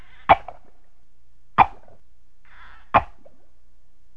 clique ici pour entendre le bruit de la pioche